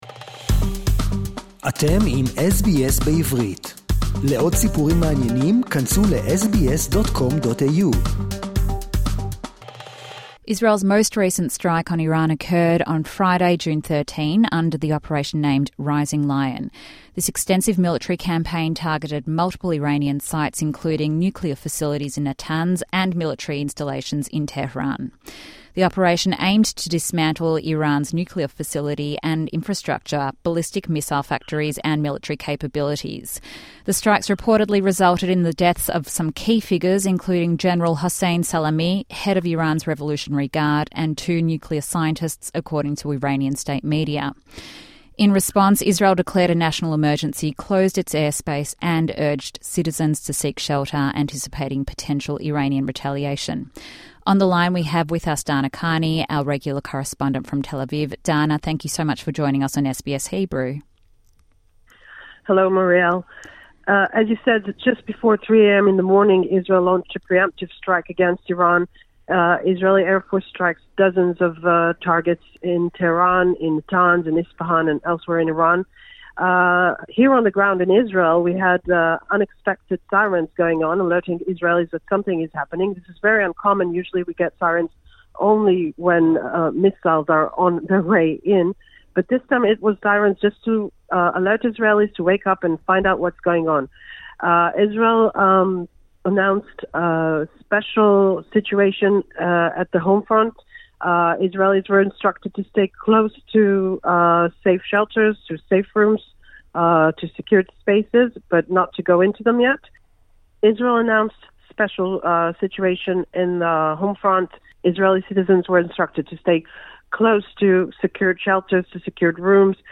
News Update from Israel following the Iran Attack